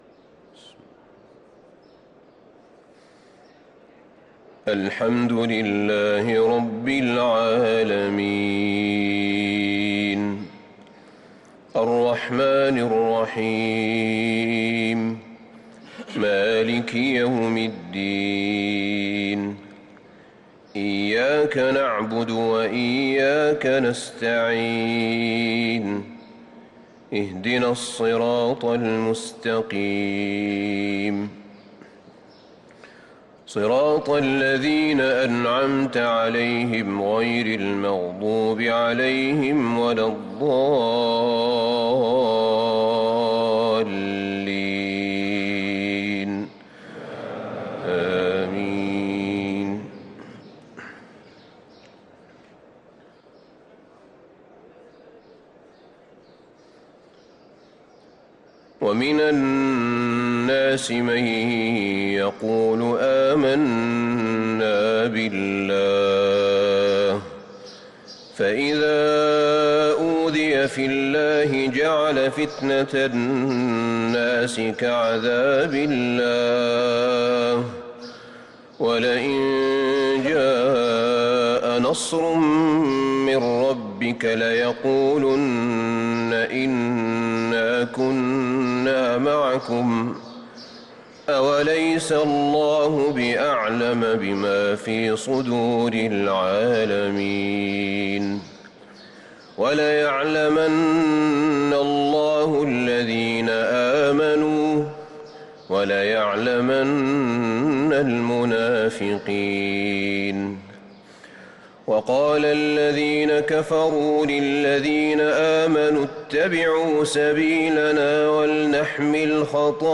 صلاة الفجر للقارئ أحمد بن طالب حميد 27 جمادي الآخر 1445 هـ